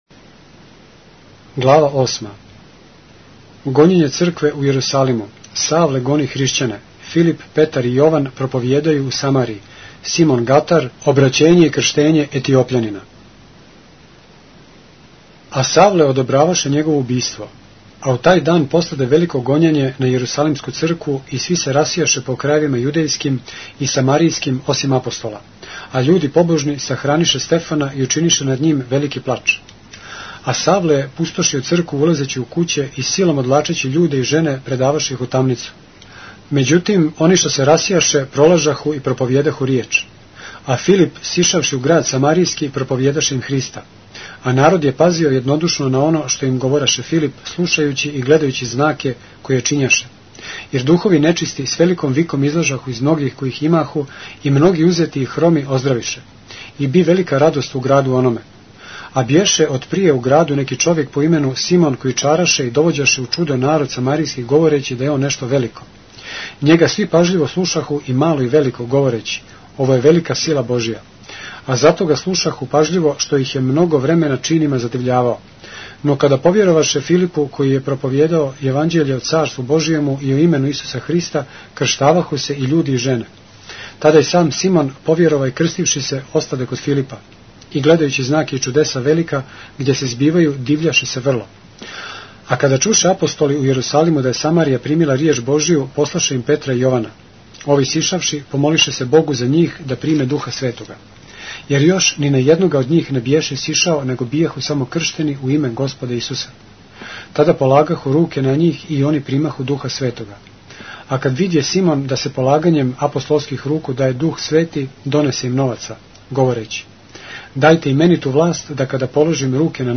поглавље српске Библије - са аудио нарације - Acts, chapter 8 of the Holy Bible in the Serbian language